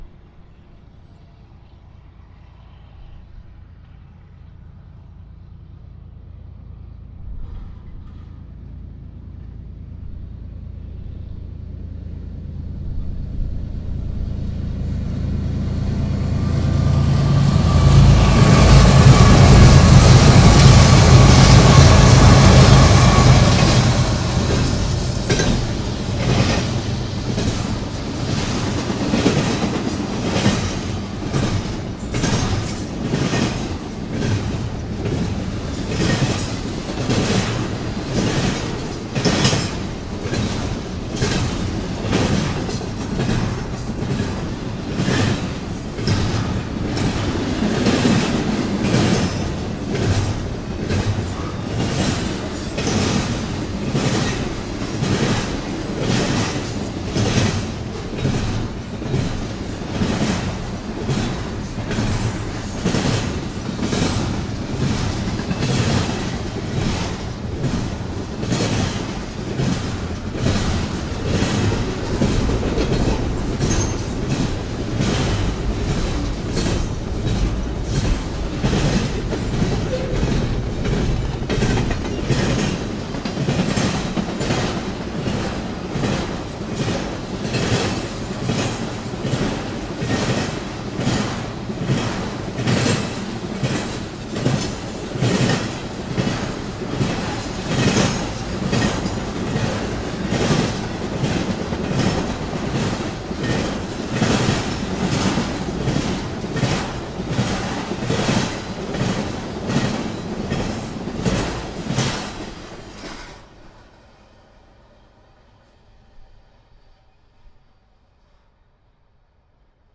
描述：在等待火车时，另一位乘客拖着她的包从我身边经过。在这个声音文件的最后，一辆经过的新干线驶来。奥林巴斯LS10.
标签： 场记录 传递 平台 火车 步行
声道立体声